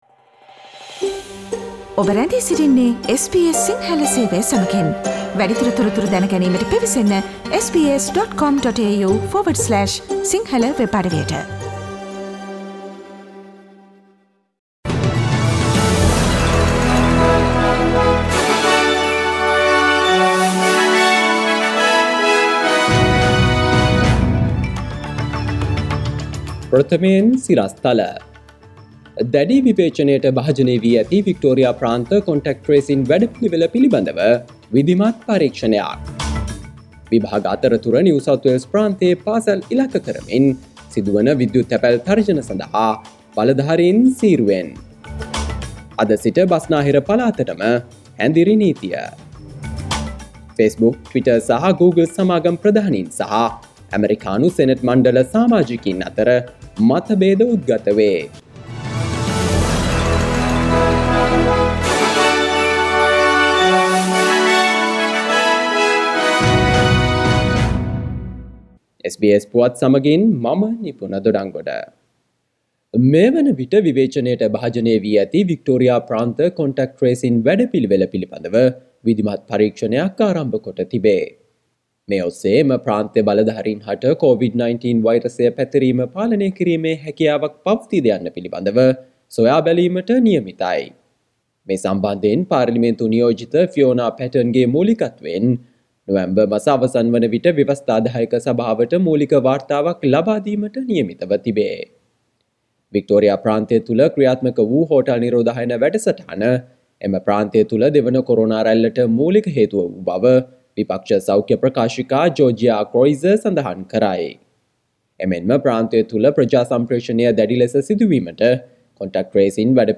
SBS සිංහල සේවයේ අද - ඔක්තෝබර් මස 29 වන බ්‍රහස්පතින්දා වැඩසටහනේ ප්‍රවෘත්ති ප්‍රකාශය.